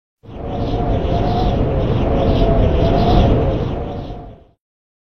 効果音 風
wind-magic.mp3